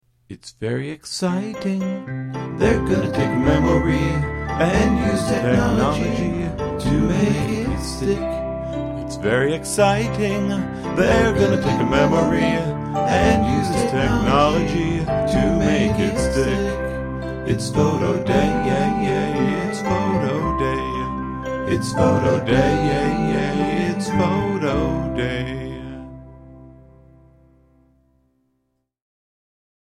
here’s a micro-tune, written and recorded today for the littlest Vortices. It was Photo Day at school.